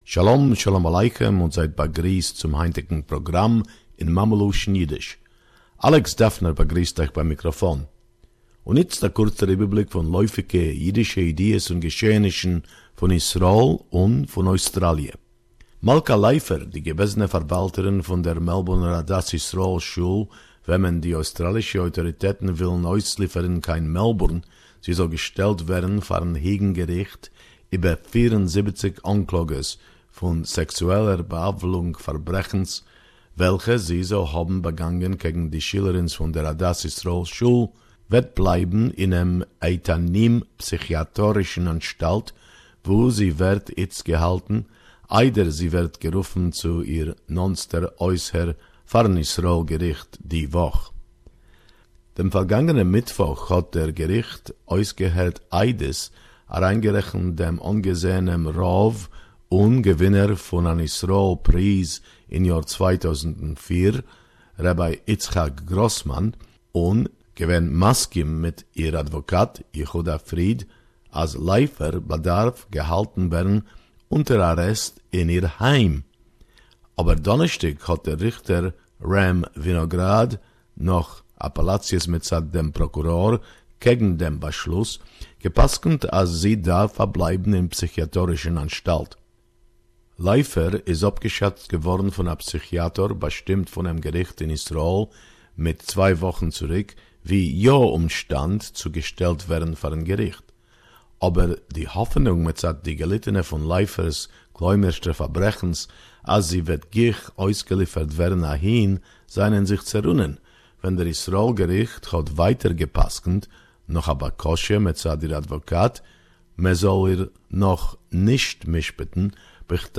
Yiddish report about Israel and Jewish current affairs, 11 March 2018